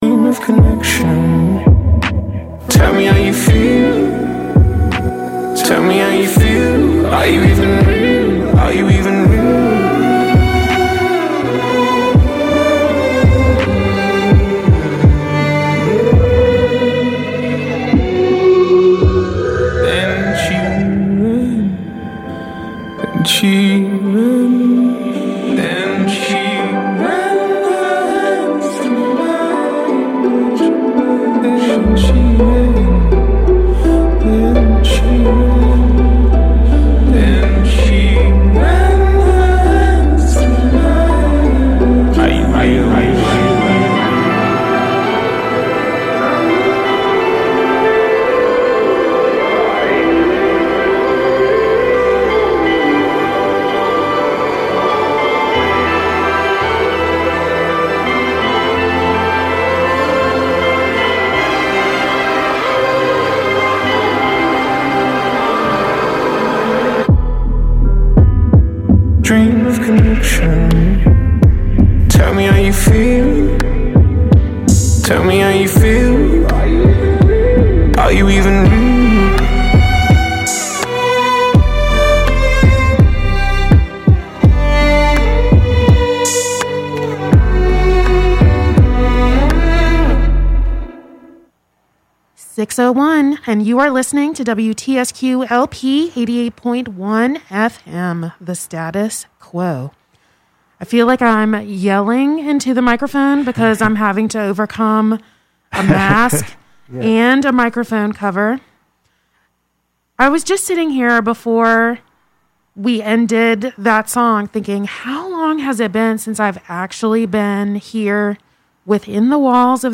Thurston Moore Interview on WTSQ (Part 1 of 2)